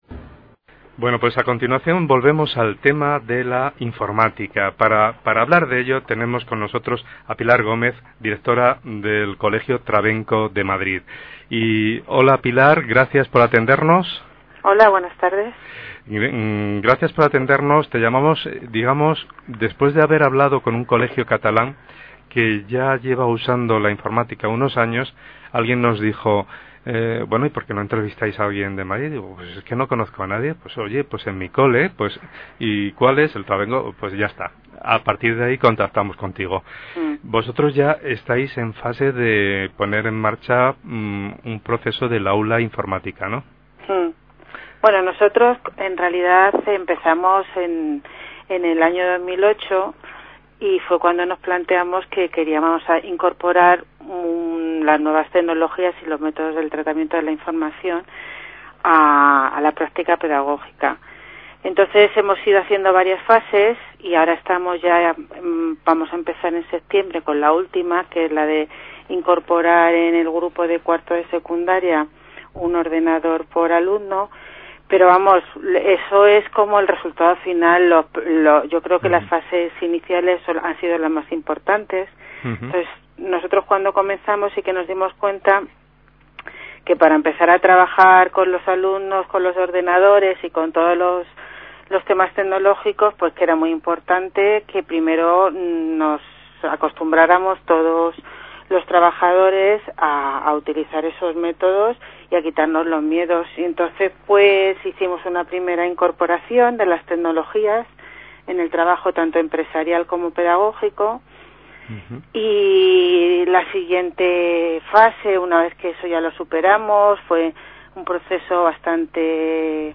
El pasado día 8 de junio, la emisora de radio de la FAPA Francisco Giner de los Ríos se puso en contacto con nnosotros para hablar de nuestro proyecto de inmersión tecnológica.